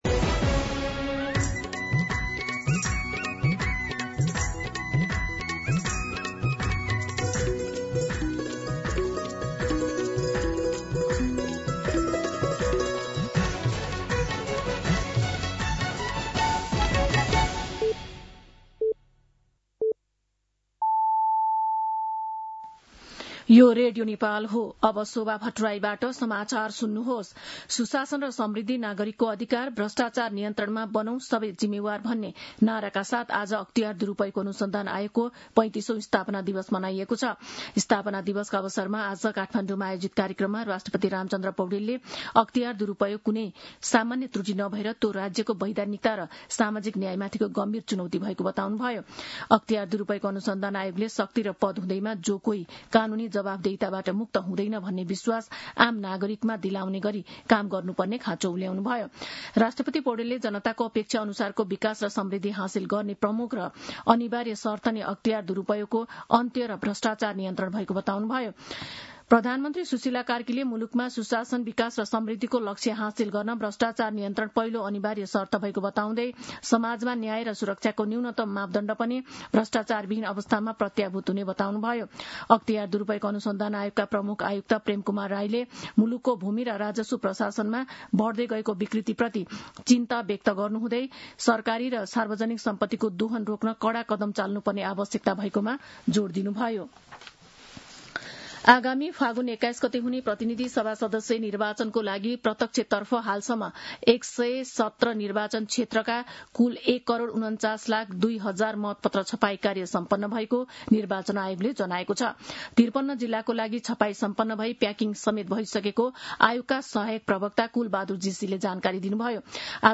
दिउँसो १ बजेको नेपाली समाचार : २८ माघ , २०८२